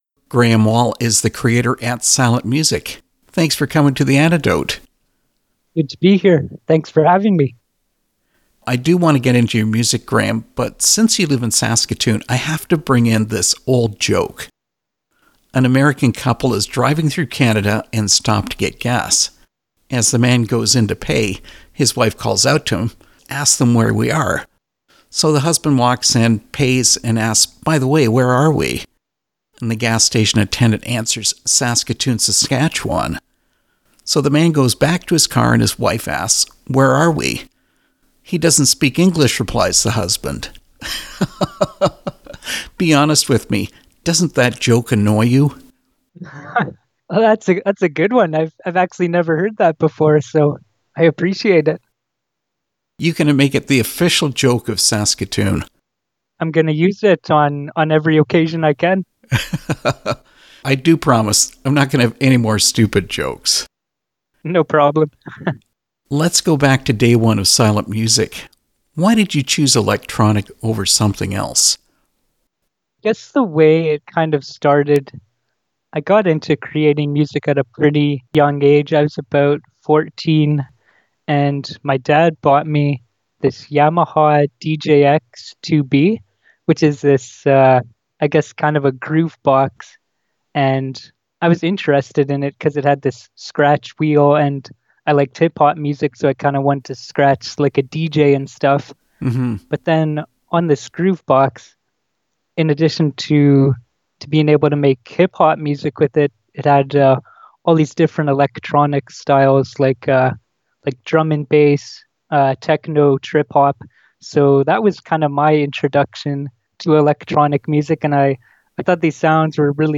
Interview with Silent Music
silent-music-interview.mp3